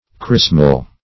Chrismal \Chris"mal\, a. [LL. chrismalis.]